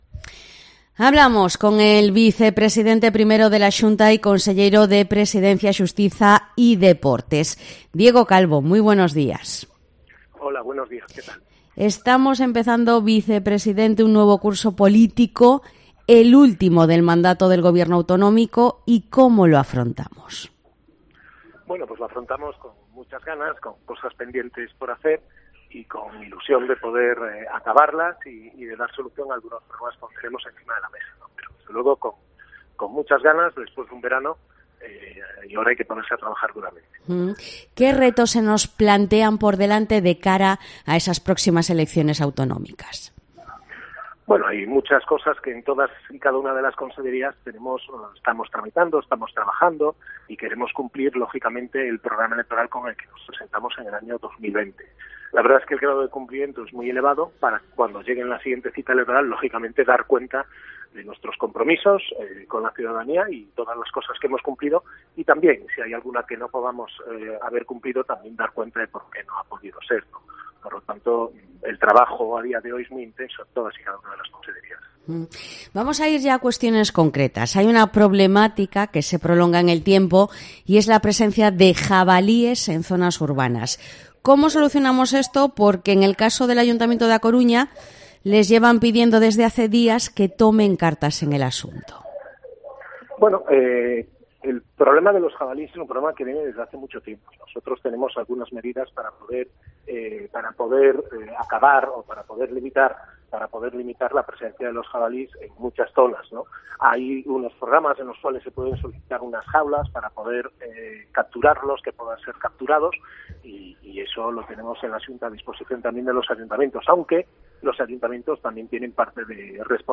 Entrevista a Diego Calvo, vicepresidente primero de la Xunta de Galicia